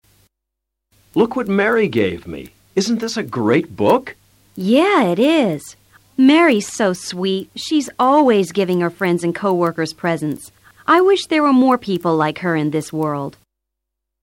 A continuación escucharás a cuatro parejas alabando o criticando a otras personas.